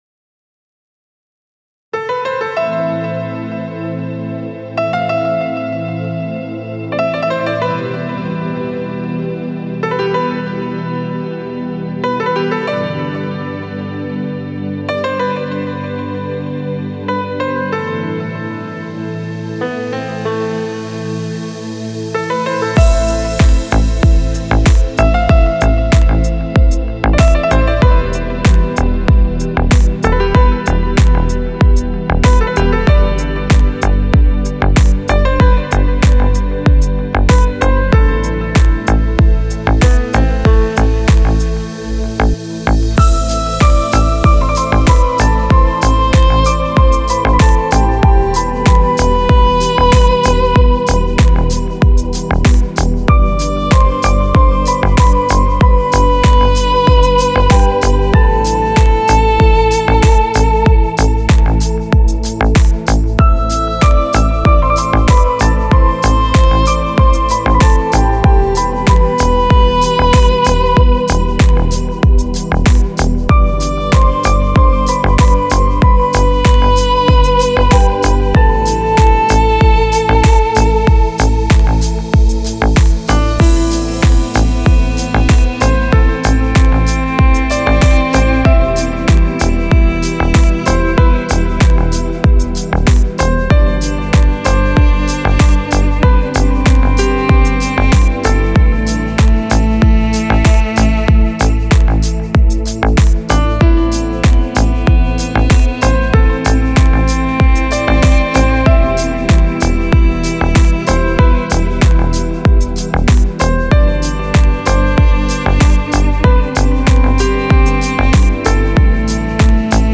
دیپ هاوس